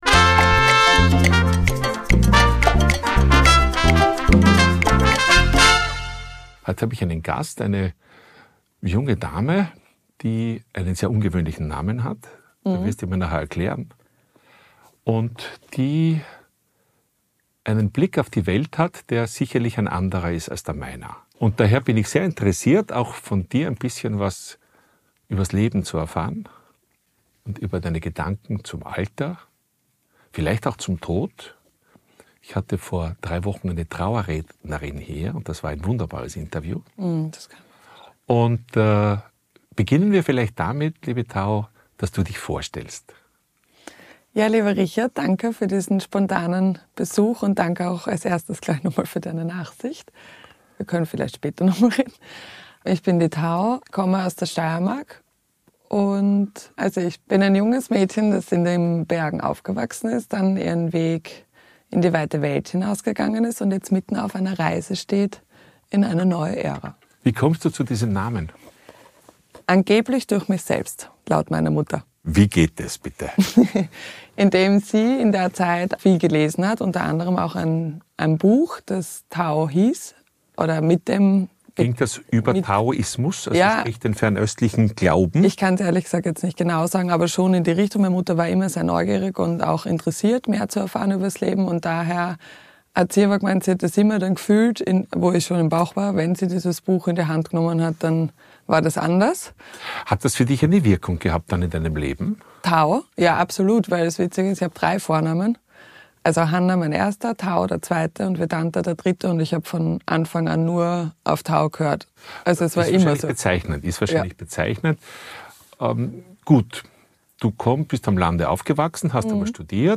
Heute im Interview